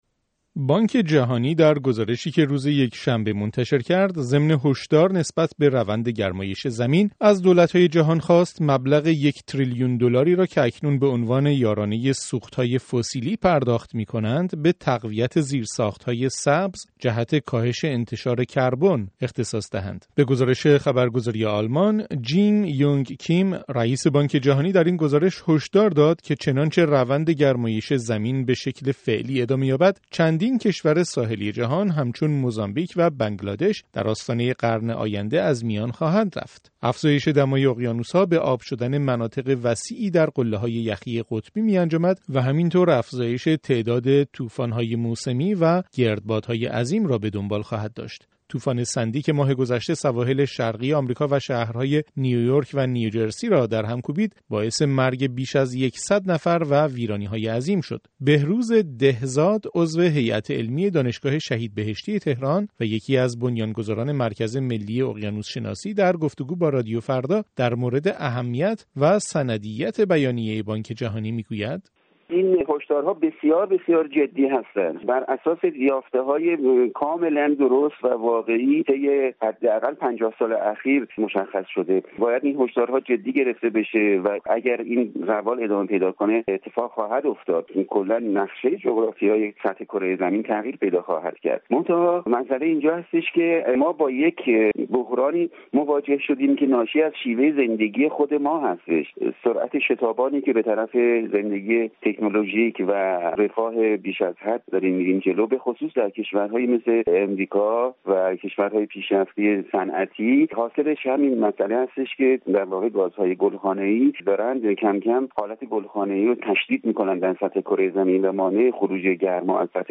گزارش رادیویی درباره تغییرات آب و هوایی در کره زمین